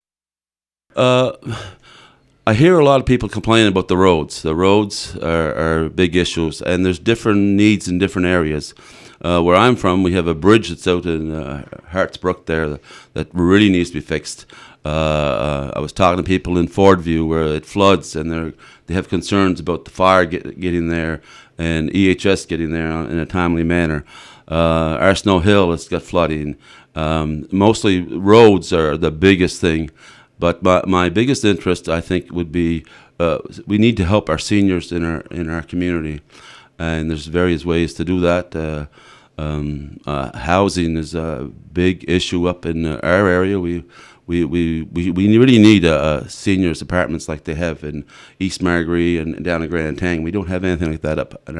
Lors d'un entretien sur les ondes de Radio CKJM, le nouveau conseiller municipal mentionne que plusieurs choses sont à améliorer dans district 2. Il veut travailler pour régler la pénurie de logements abordables surtout pour les aînés.